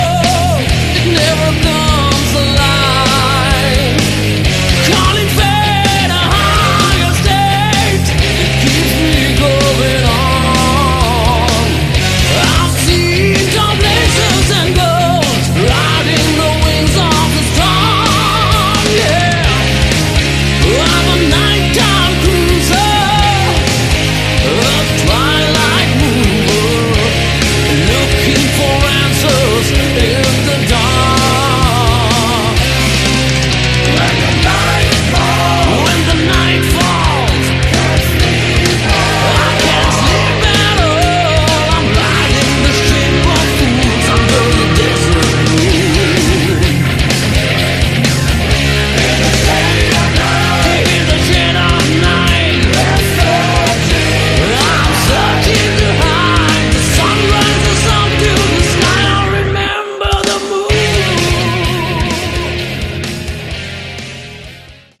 Category: Hard Rock
Vocals
Guitars
Bass
Drums
Keyboards
Backing Vocals